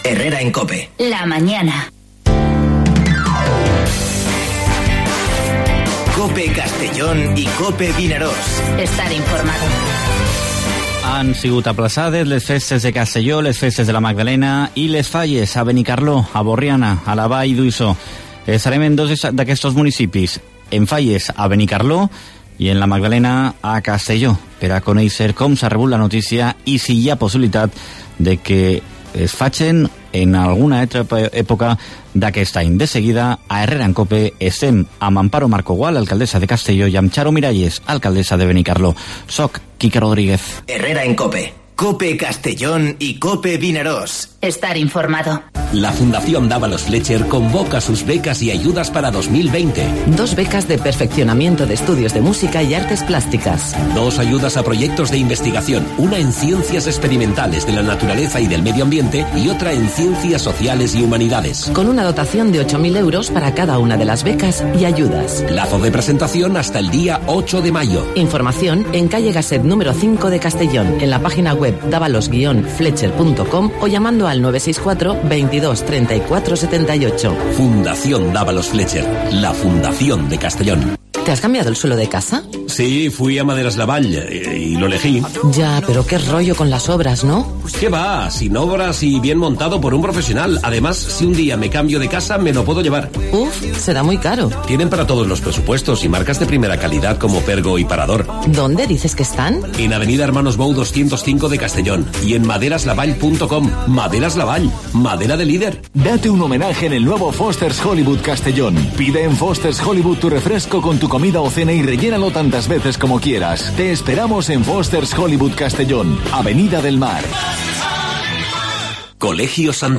Dos historias que debes conocer en Herrera y Mediodía COPE en la provincia de Castellón: Aplazamiento de Fallas y Fiestas de la Magdalena con alcaldesas de Benicarló Xaro Miralles y Castellón, Amparo Marco, así como posibles fechas para su celebración; y el coronavirus deja importantes pérdidas para la economía y el turismo de Castellón.